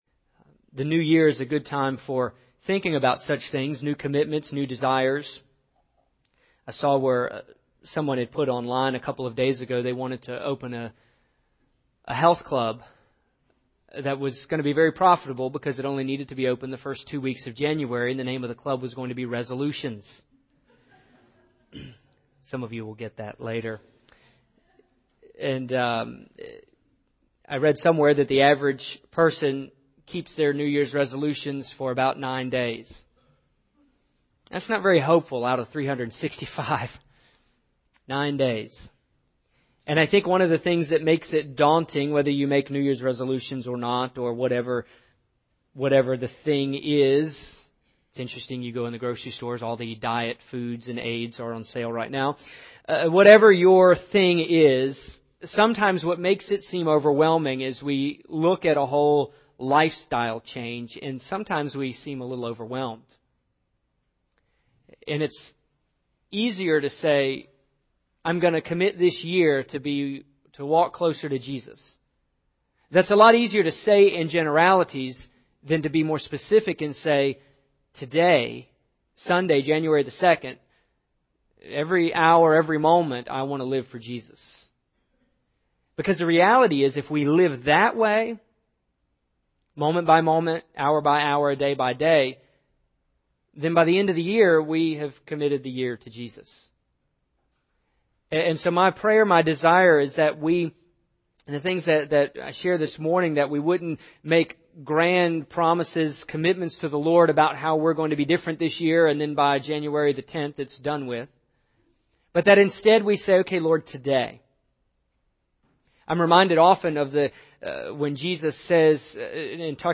This past Sunday, I preached a sermon entitled, “Where are we going?”